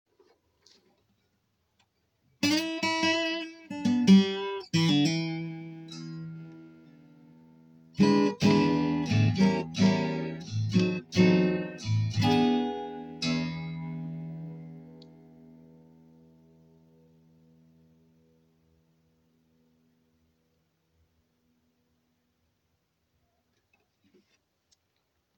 a rather short recording between two lessons at school, with my smartphone and a thin plectrum:
Höfner Dreadnought.mp3
h-c3-b6fner-dreadnought.mp3